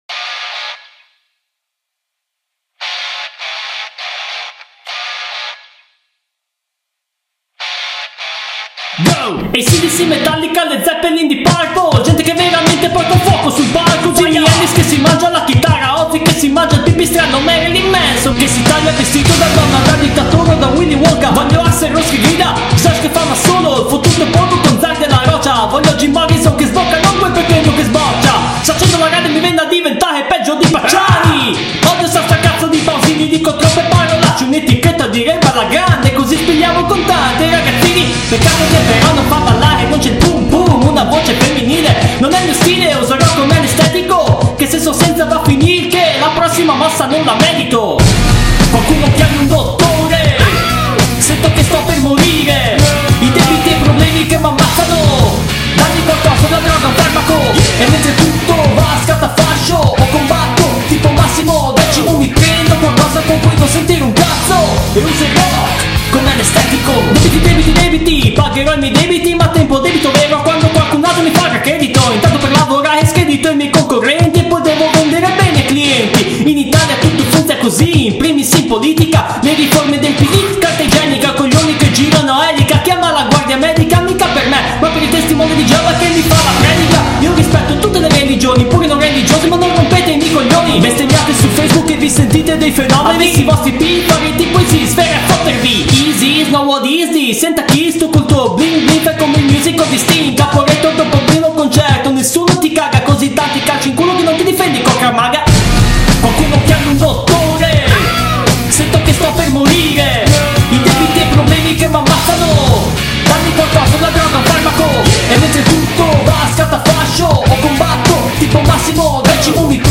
Luogo esecuzioneModena
GenereHip Hop / Rap